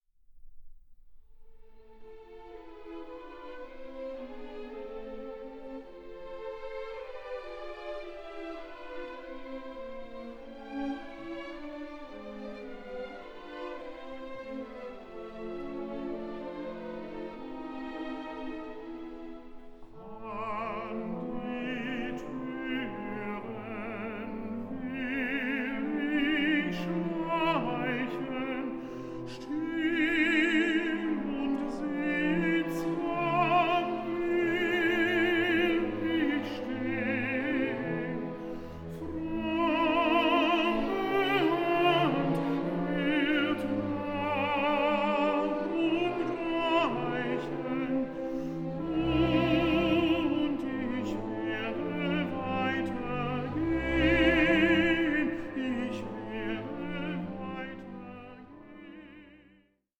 German tenor